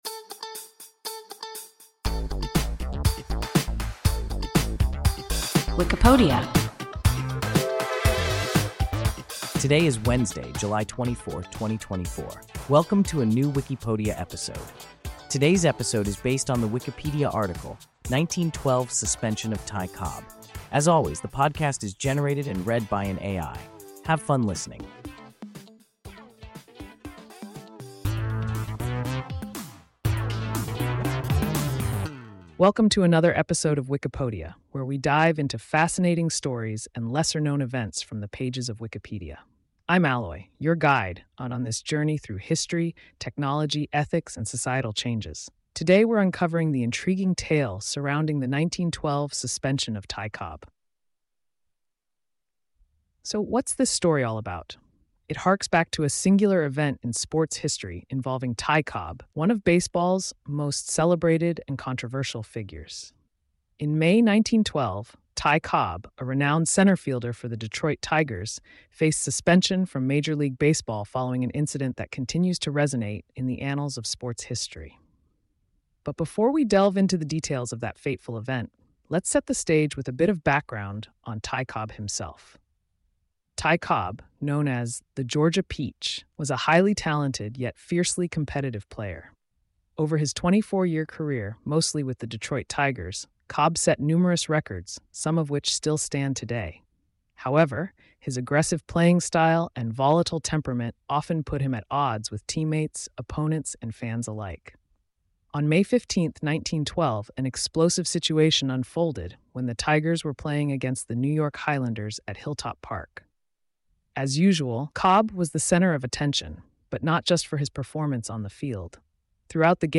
1912 suspension of Ty Cobb – WIKIPODIA – ein KI Podcast